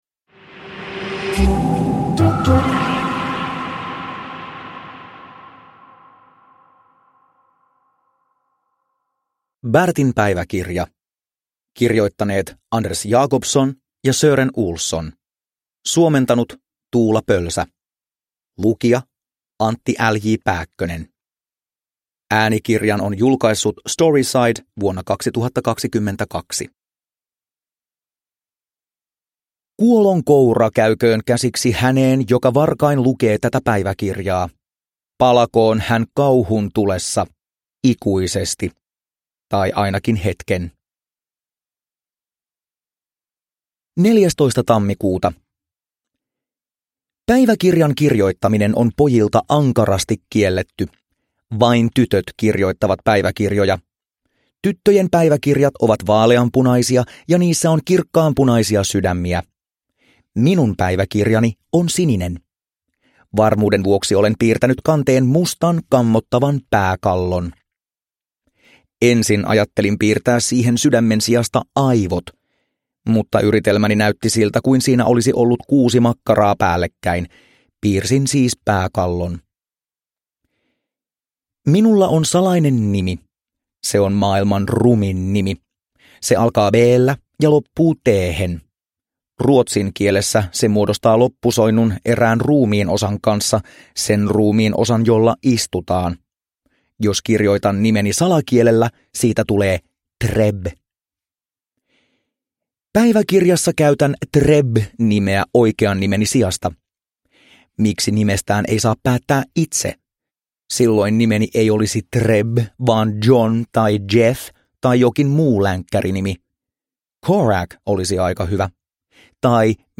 Bertin päiväkirja – Ljudbok – Laddas ner